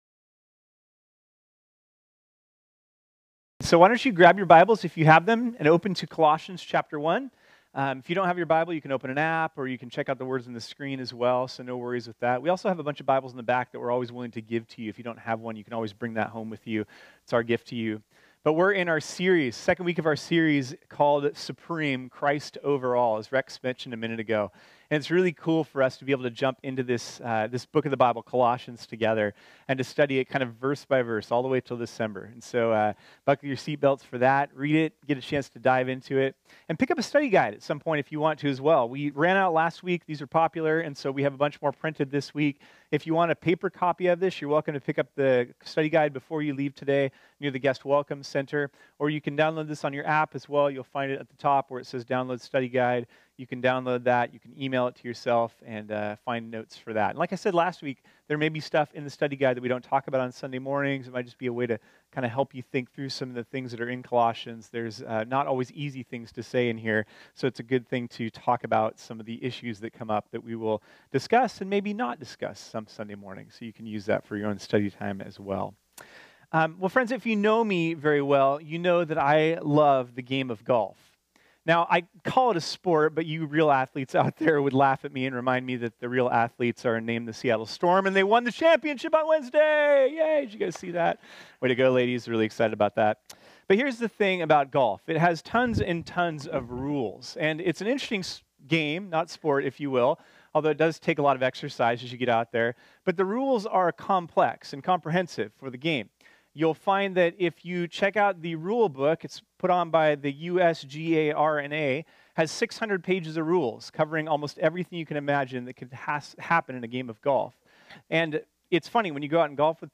This sermon was originally preached on Sunday, September 16, 2018.